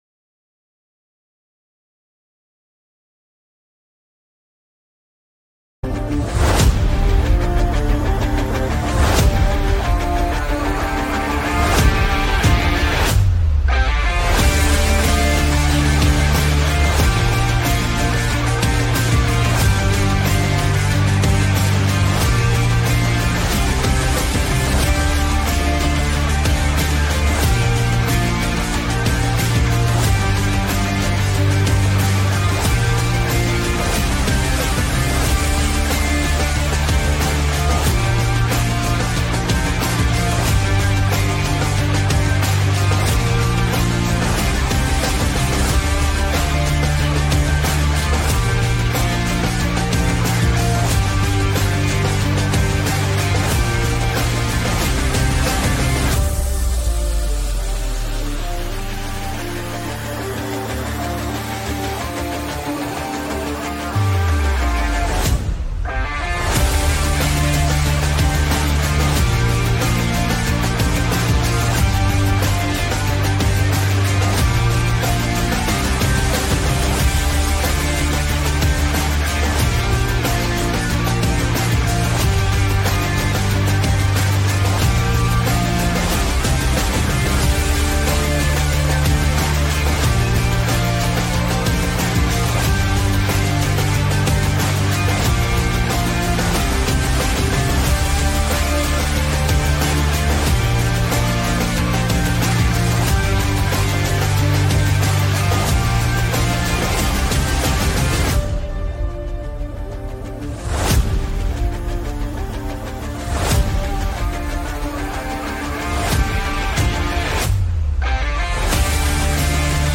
48:58 Play Pause 22d ago 48:58 Play Pause Play later Play later Lists Like Liked 48:58 🔥 Are modern apostles and prophets truly biblical—or dangerously off track? In this explosive conversation